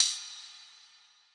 Open Hats
OHAT - BOTTOM.wav